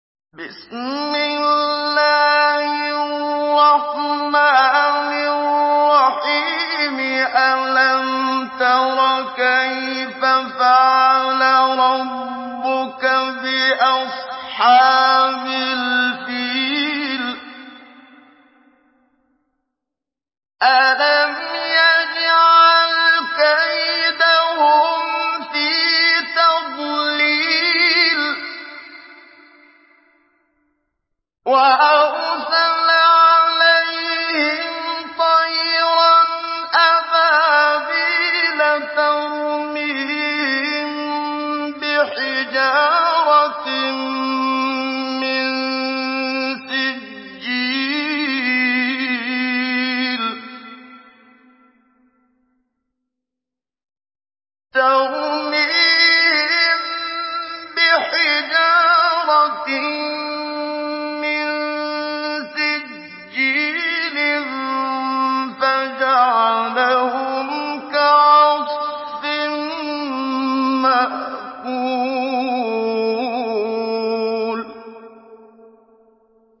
سورة الفيل MP3 بصوت محمد صديق المنشاوي مجود برواية حفص
تحميل سورة الفيل بصوت محمد صديق المنشاوي